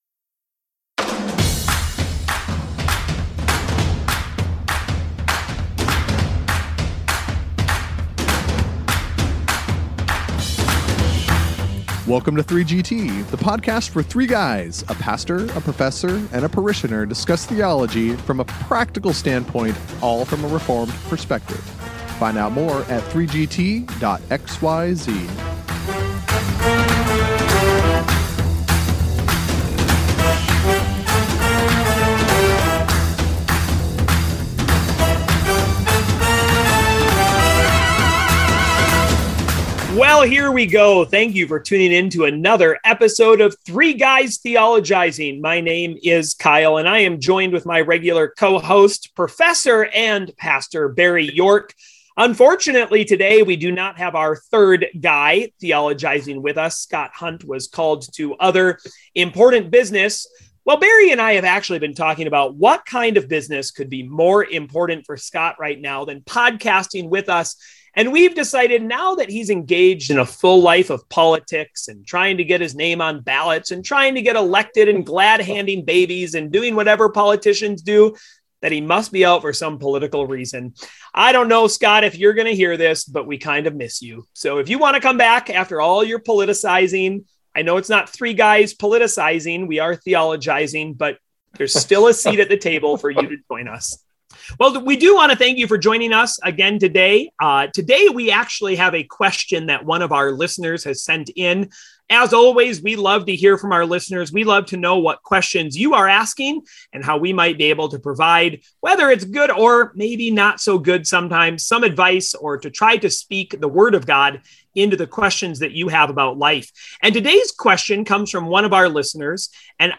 As such, their tone turns more serious.